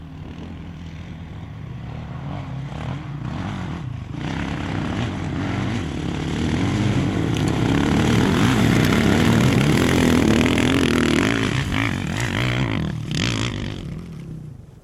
越野车 " 摩托车 越野车 摩托车上的怠速启动 加速快
描述：摩托车越野摩托车越野摩托车越野加速快速